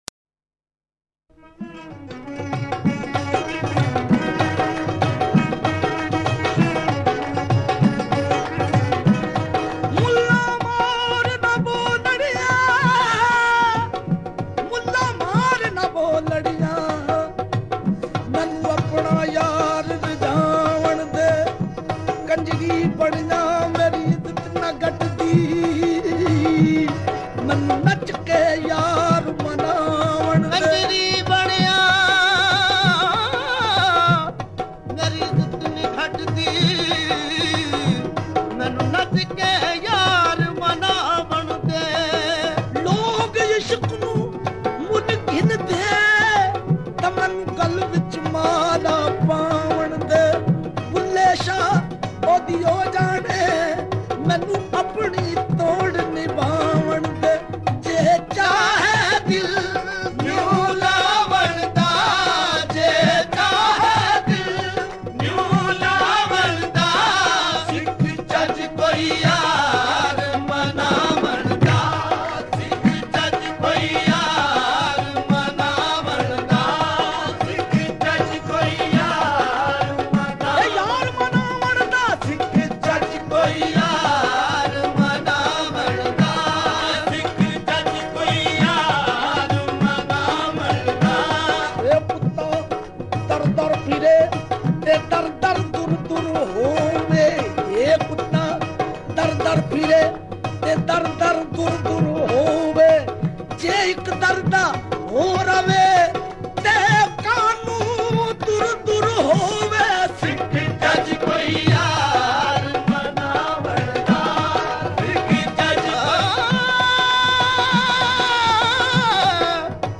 Qawwalis